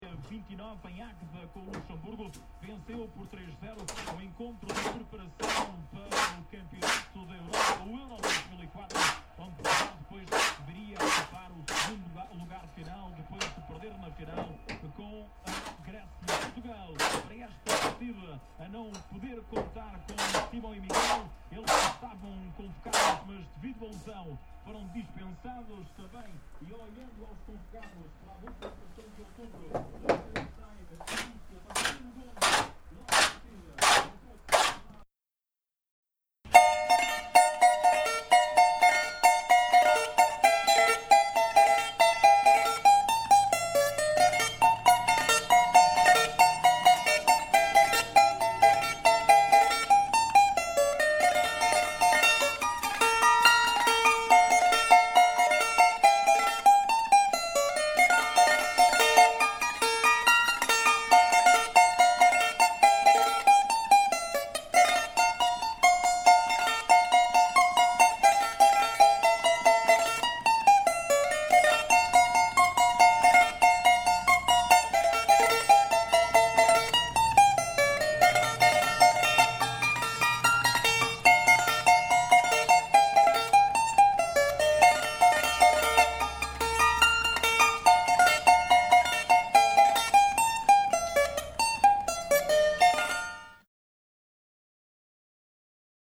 CAVAQUINHO